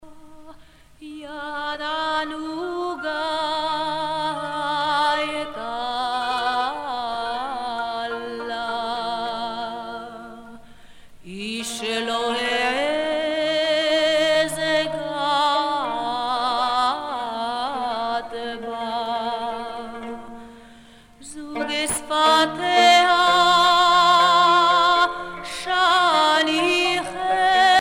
Influence du folklore yéménite et du folklore arabe
Pièce musicale éditée